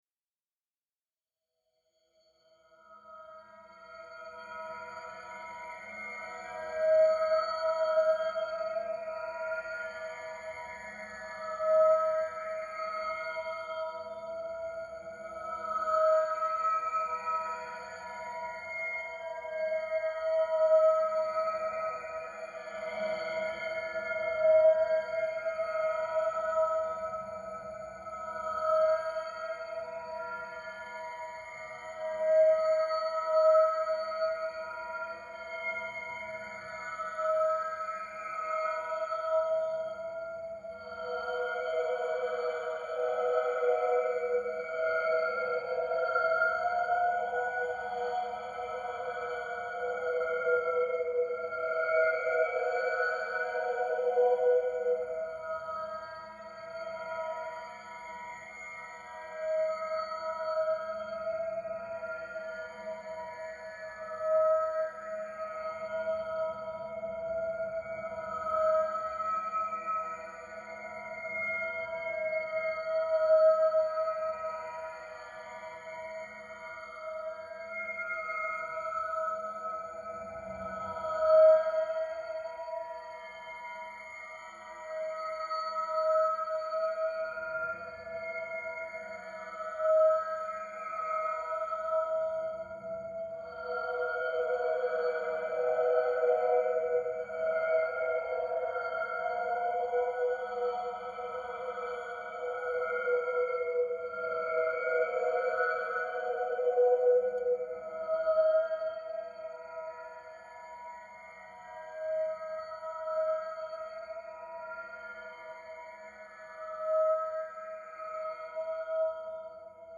Фантастика: Инопланетная песня